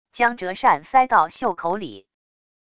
将折扇塞到袖口里wav音频